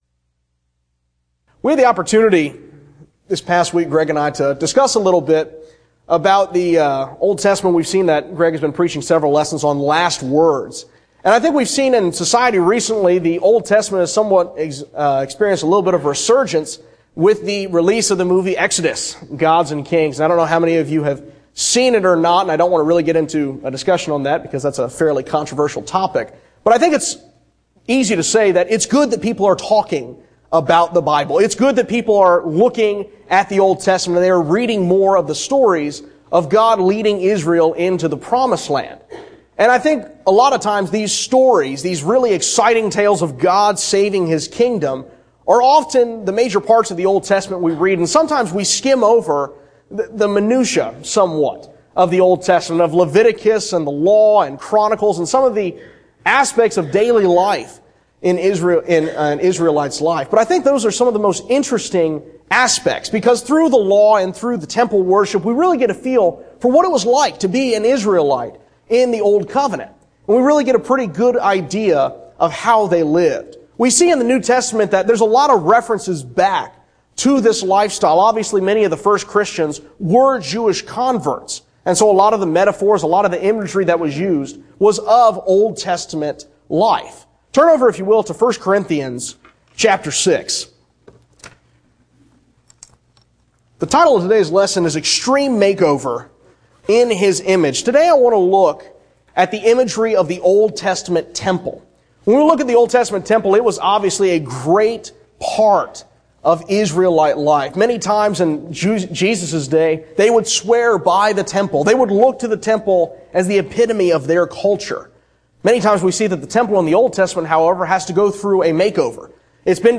Service: Sun PM Type: Sermon